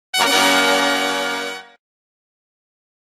Ta-Da_-_Sound_Effect.m4a